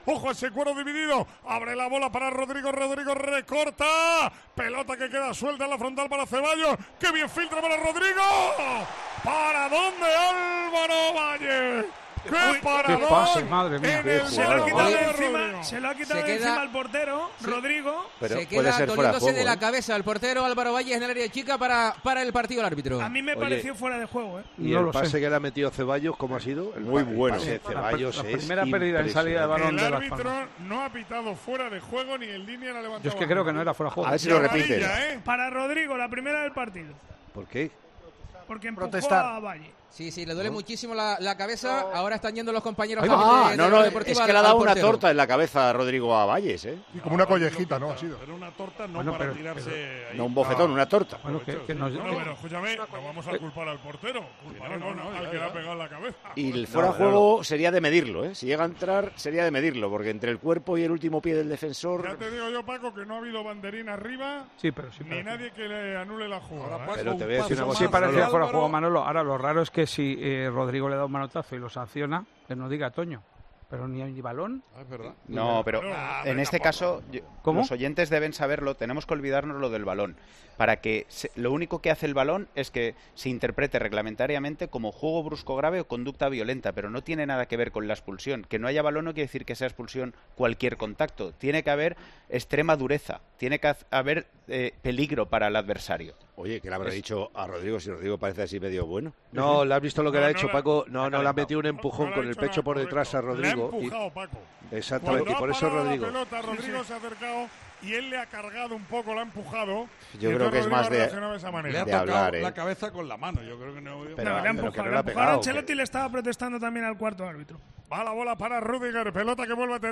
Una vez vista la jugada, el excolegiado internacional Mateu Lahoz la explicó en Tiempo de Juego durante la retransmisión del encuentro. En el siguiente audio podrás escuchar la acción con la narración de Manolo Lama desde tierras canarias.